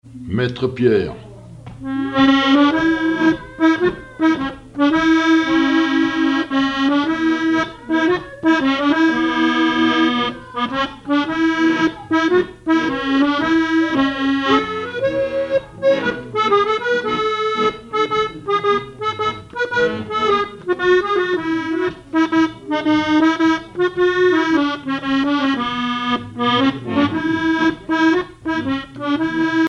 accordéon(s), accordéoniste
danse : marche
Pièce musicale inédite